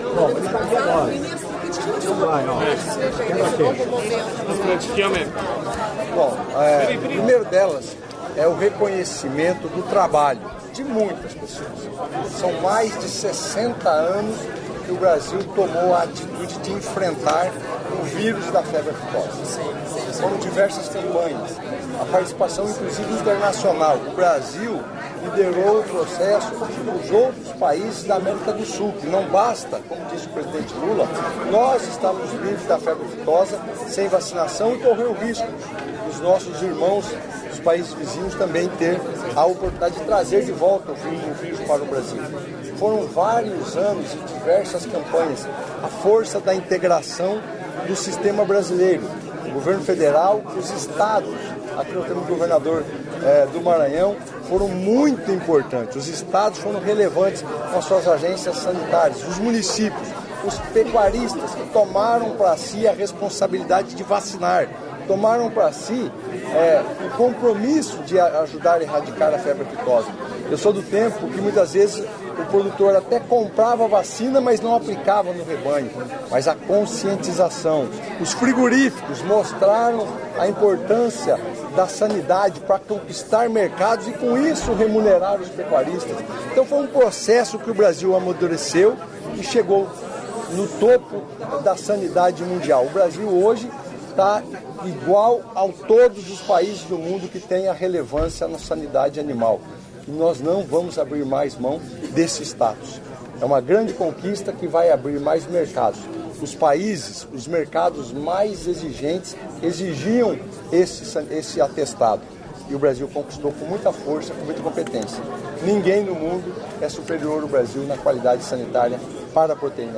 Íntegra do discurso do ministro em exercício do Desenvolvimento, Indústria, Comércio e Serviços, Márcio Fernando Elias Rosa, e do presidente da Agência Brasileira de Promoção de Exportações e Investimentos (ApexBrasil), Jorge Viana, na cerimônia de encerramento do Fórum Empresarial Brasil-França, nesta sexta-feira (6), em Paris.